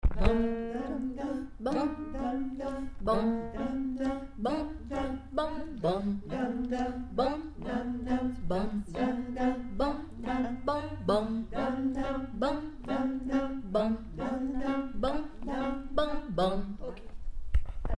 alto1 2 mes 41 a 52